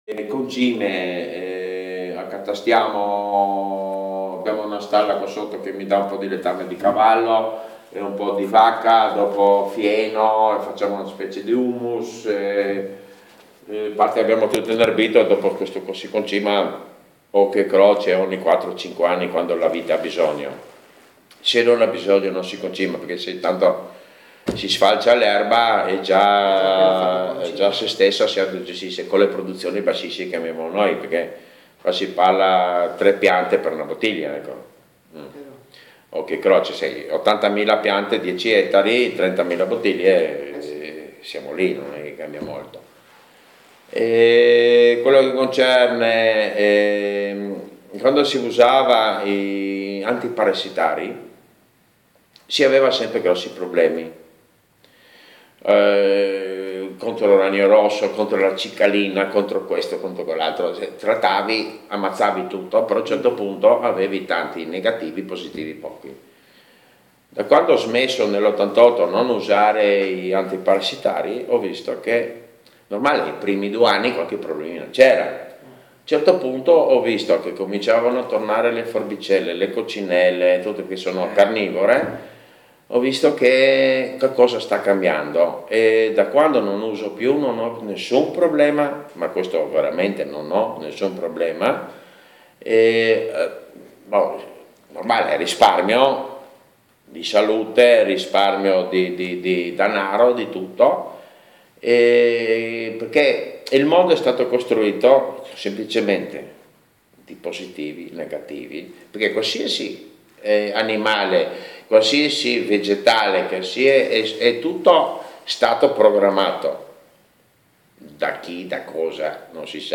Ascoltiamolo mentre parla del suo lavoro: